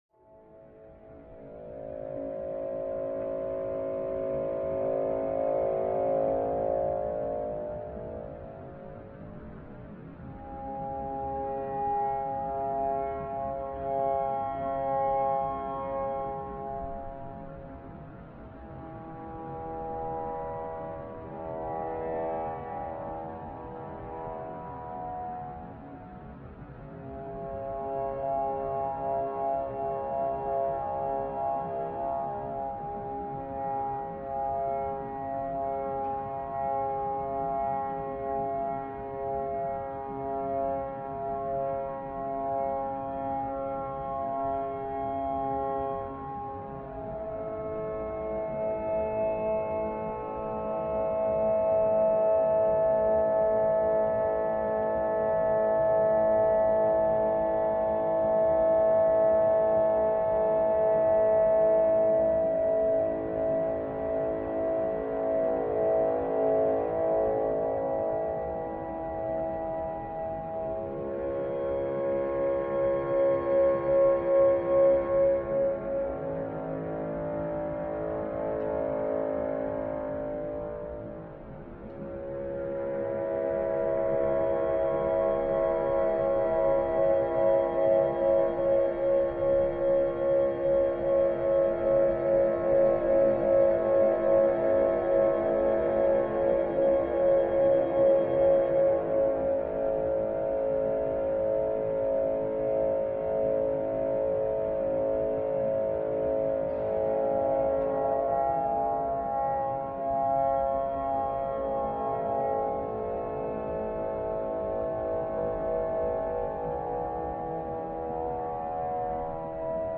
Ambiente432, part of the museum’s permanent collection, is “played” by visitors moving through and activating the space—and impacting their own experience with the kinetic sound sculpture. The installation is tuned to 432 Hz, the vibration frequency known as “Verdi’s A.”